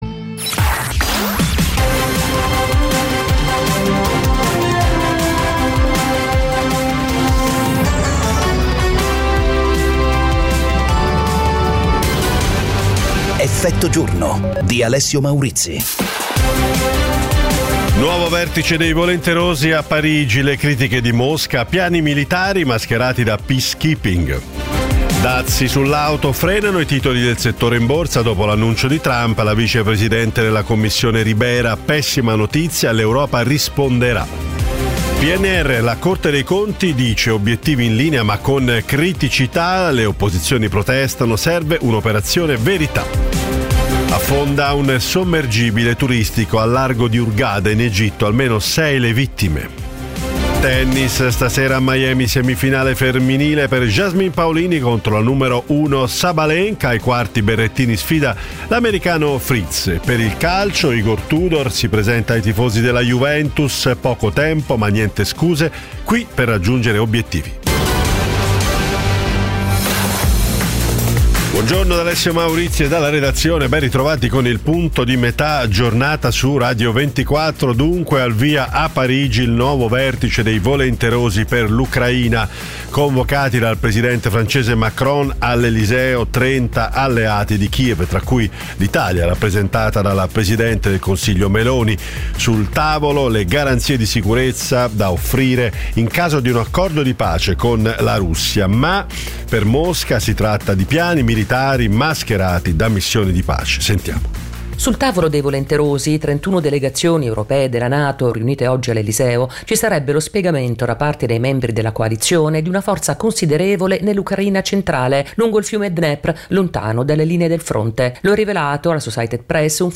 Tutto quello che è accaduto in Italia e nel mondo ed è utile sapere. Effetto giorno è la trasmissione quotidiana che getta lo sguardo oltre le notizie, con analisi e commenti per capire ed approfondire l'attualità attraverso ospiti in diretta e interviste: politica, economia, attualità internazionale e cronaca italiana.